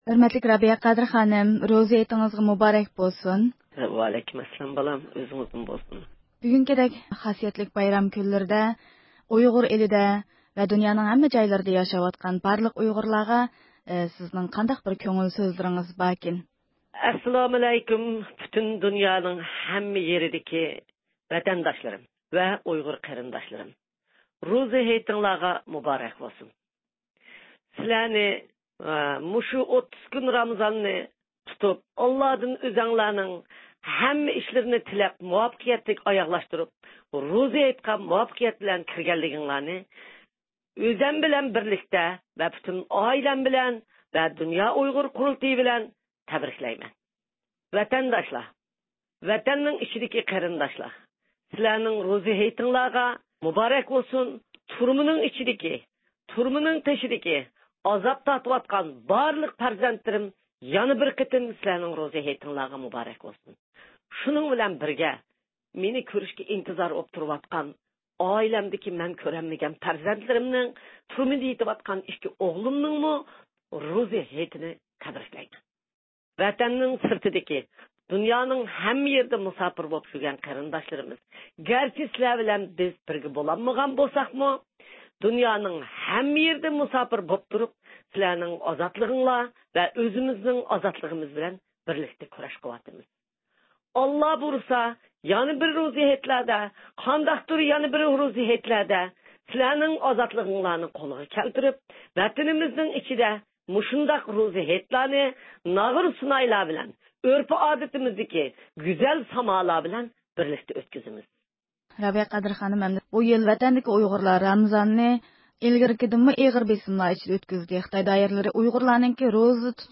بۇ مۇقەددەس كۈنلەردە ئۇيغۇر مىللىي ھەرىكىتىنىڭ رەھبىرى رابىيە قادىر خانىم رادىئومىز ئارقىلىق ۋەتەن ئىچى ۋە سىرتىدىكى بارلىق ئۇيغۇر قېرىنداشلارغا ئوتلۇق سالام يوللىدى.